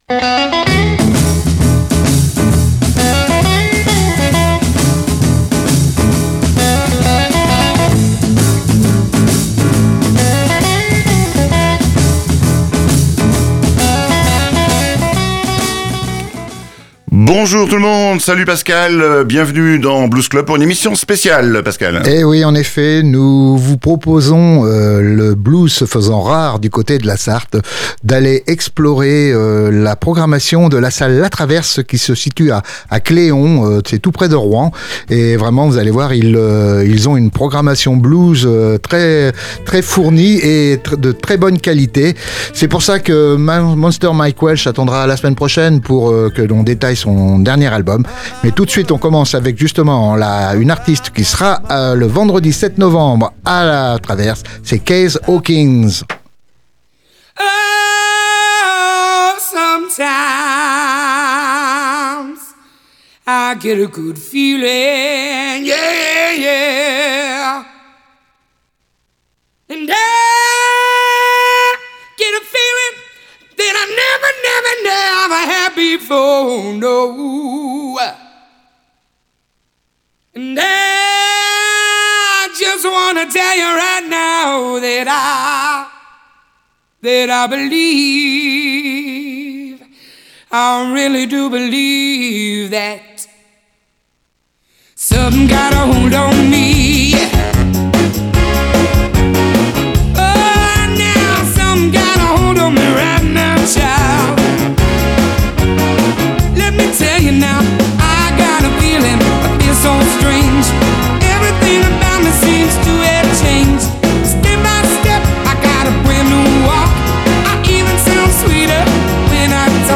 Dans sa belle salle « la Traverse », une très belle programmation Blues vous y attend : dans ce numéro 504, Blues Club vous la présente en musique !